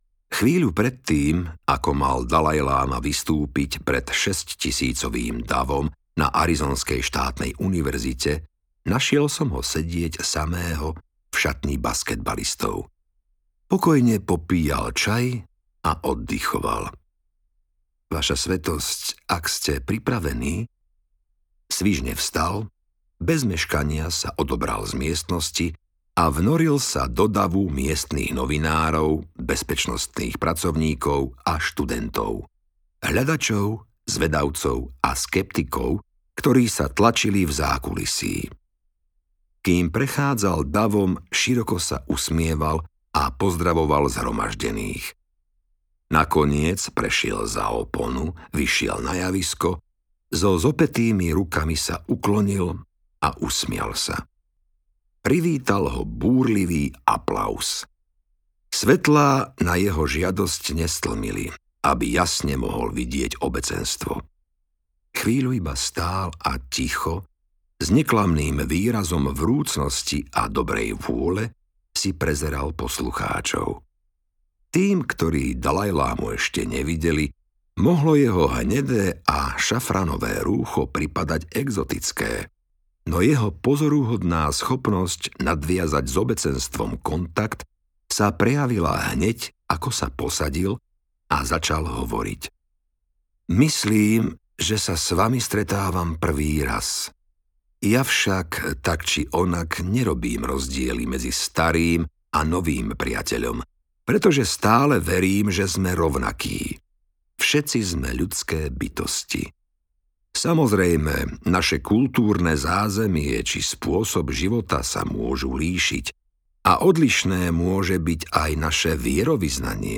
Ako byť šťastný audiokniha
Ukázka z knihy